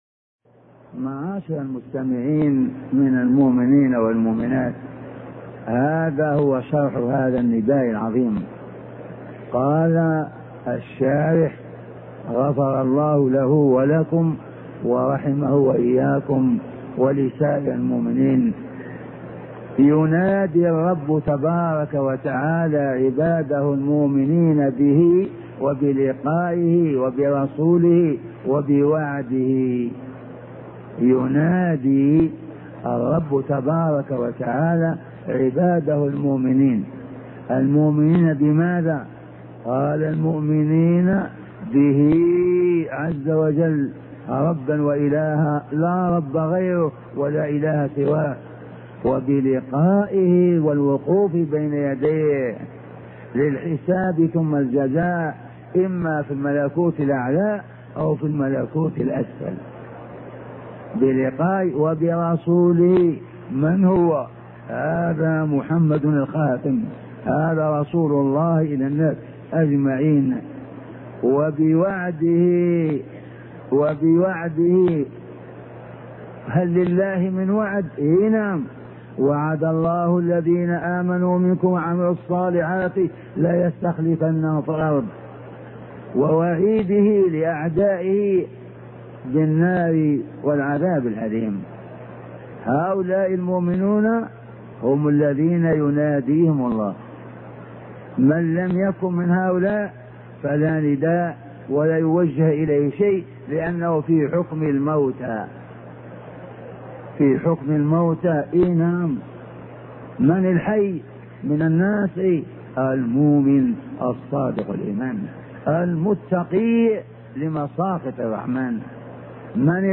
شبكة المعرفة الإسلامية | الدروس | نداءات الرحمن لأهل الإيمان 029 |أبوبكر الجزائري